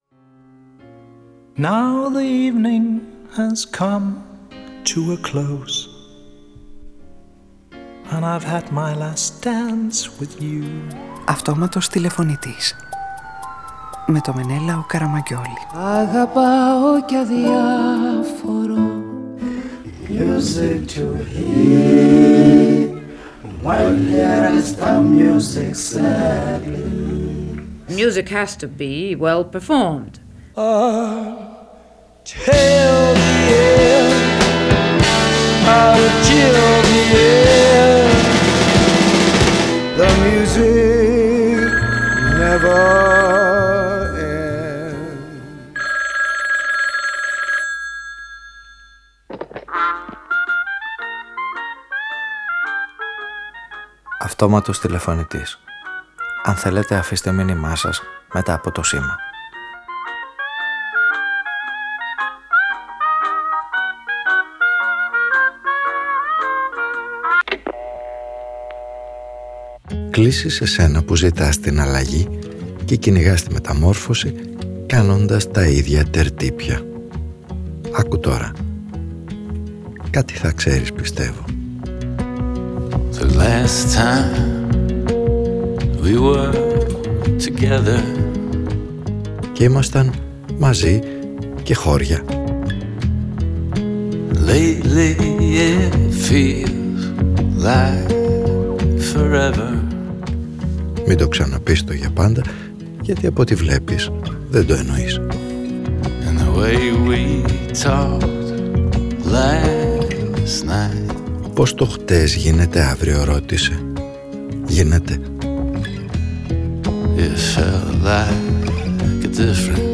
Ο ήρωας της σημερινής ραδιοφωνικής ταινίας στήνει ένα νέο (σημαντικό) μέλλον και επιδιώκει μια μεταμόρφωση που ίσως έχει ήδη συντελεστεί.
Παραγωγή-Παρουσίαση: Μενέλαος Καραμαγγιώλης